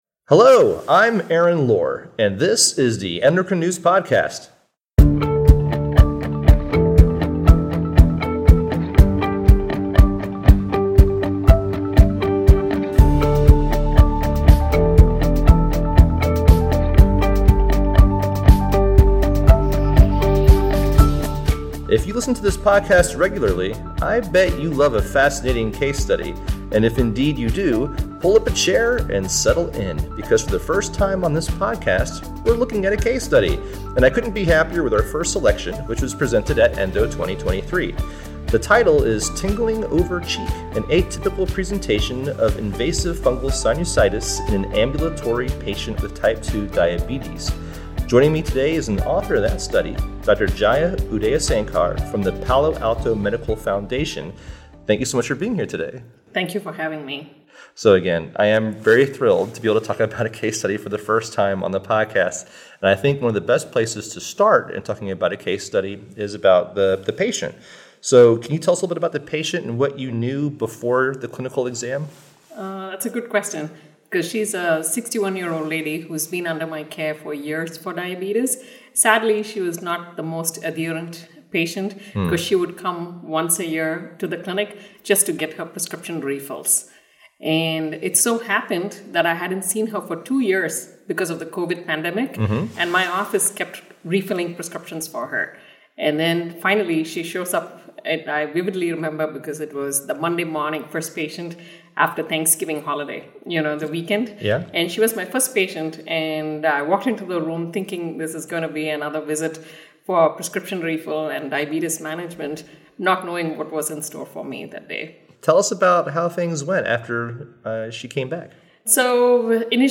From ENDO 2023